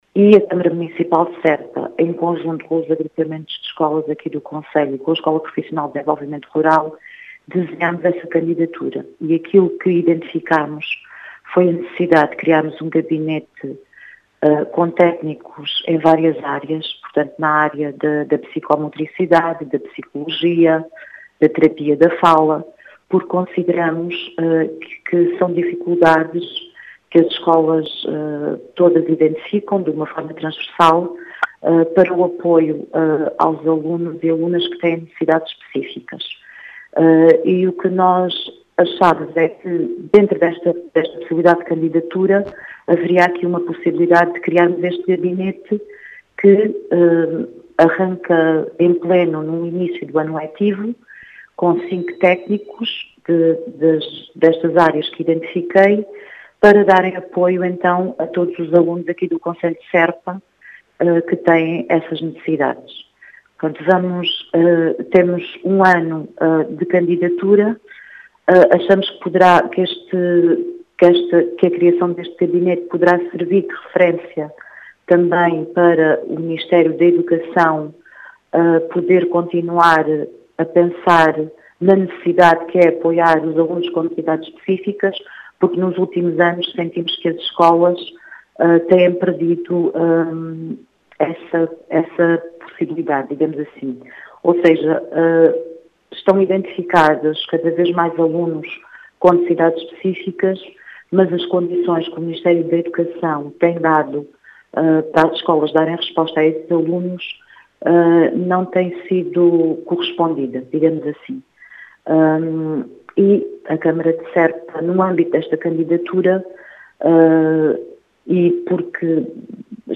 As explicações são da vereadora da Câmara de Serpa,  Odete Borralho.